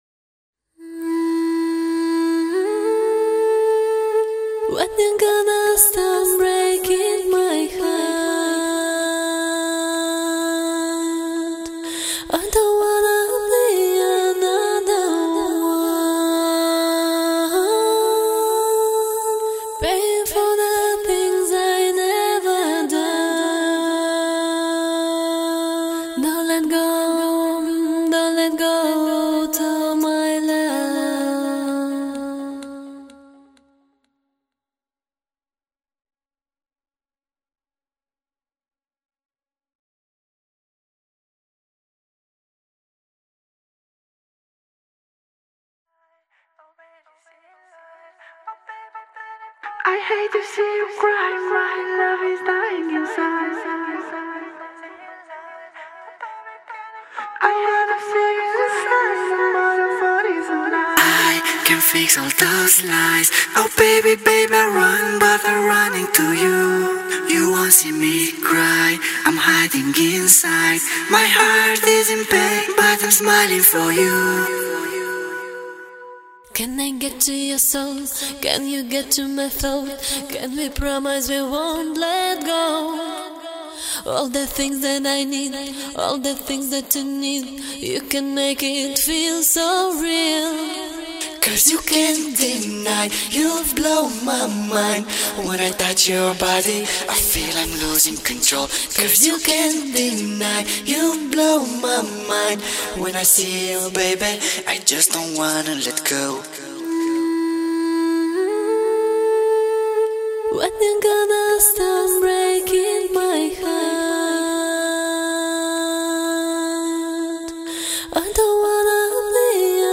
Главная » Файлы » Акапеллы » Скачать Зарубежные акапеллы